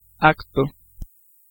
Ääntäminen
IPA : /diːd/ US : IPA : [diːd]